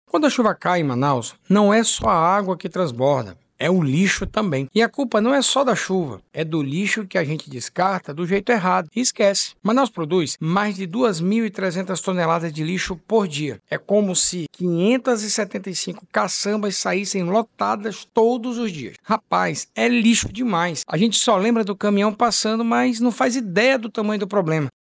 De acordo com o poder municipal, a cidade produz mais de 2,3 mil toneladas de resíduos por dia, o equivalente a cerca de 575 caminhões caçamba cheios, e boa parte desse lixo acaba indo parar em bueiros, igarapés e redes de drenagem, agravando as alagações e provocando danos à infraestrutura urbana, como destaca o vice-prefeito, Renato Júnior.
Sonora-1-Renato.mp3